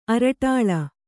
♪ araṭāḷa